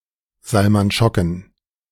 Salman Schocken (German: [ˈzalman ˈʃɔkn̩]
Salman Schocken ("S" in Salman pronounced "Z") was born on October 30, 1877,[1] in Margonin, Posen, German Empire (today Poland), the son of a Jewish shopkeeper.[2] In 1901, he moved to Zwickau, a German town in southwest Saxony, to help manage a department store owned by his brother, Simon.